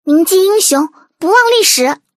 配音 村川梨衣